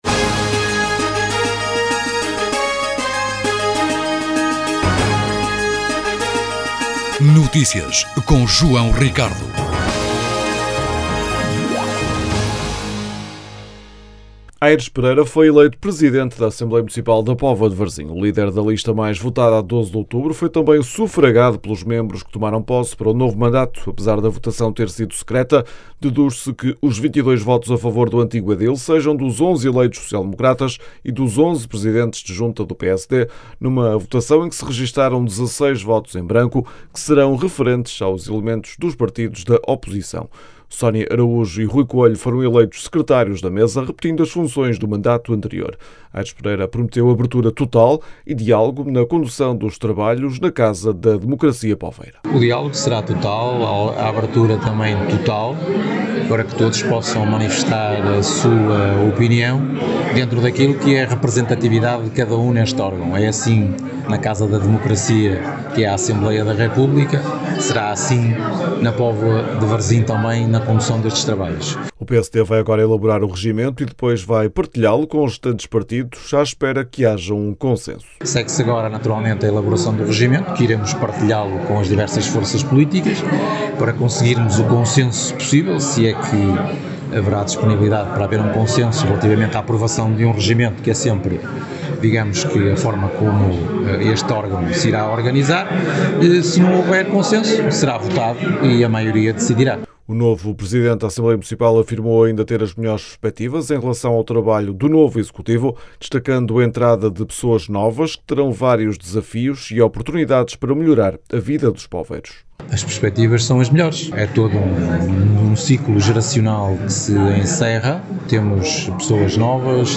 Aires Pereira reconheceu também que terá de haver uma postura de abertura do PSD em função dos resultados eleitorais, conjugando os diferentes pesos políticos e respetivos programas. As declarações podem ser ouvidas na edição local.